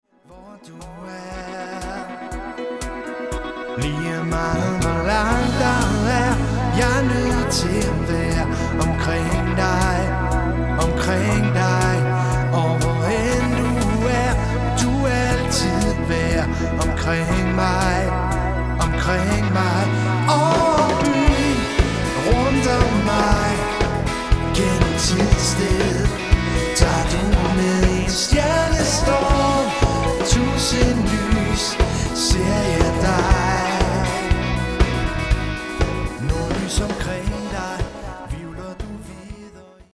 Professionel - Allround party band
• Coverband